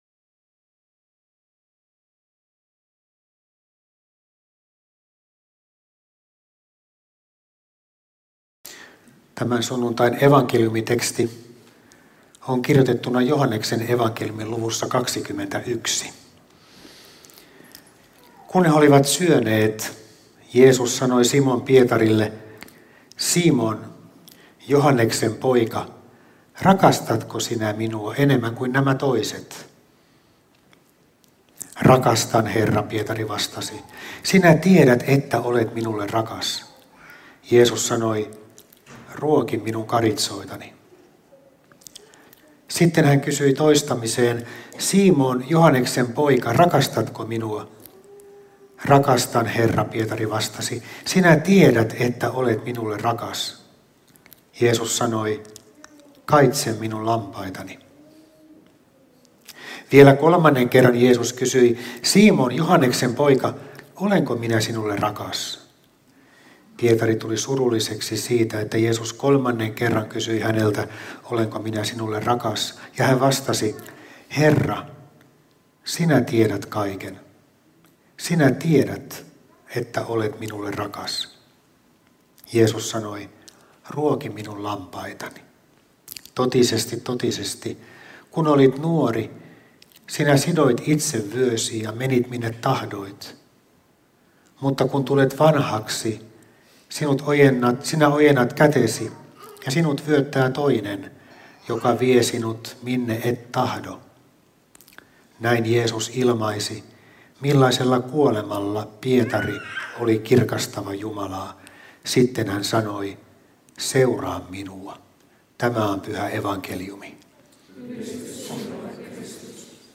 saarna Helsingin Luther-kirkossa 2. sunnuntaina pääsiäisestä Tekstinä Joh. 21:15–19